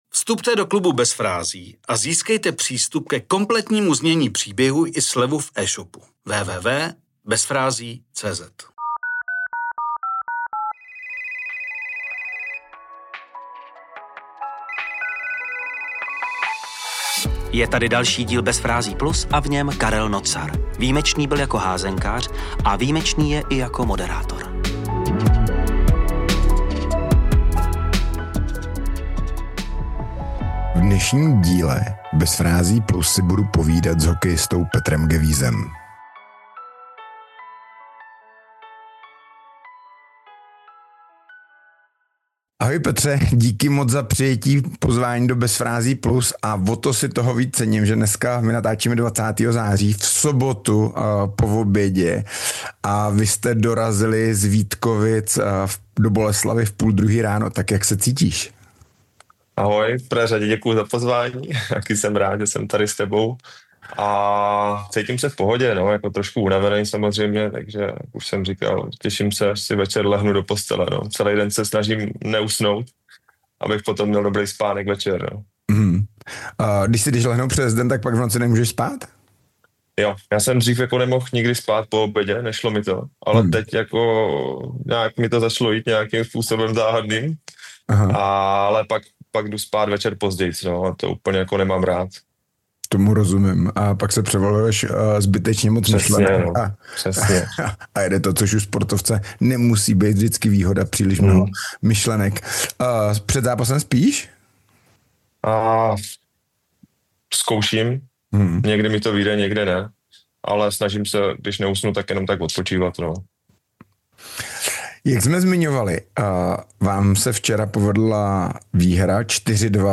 🎧 CELÝ ROZHOVOR pouze pro členy KLUBU BEZ FRÁZÍ.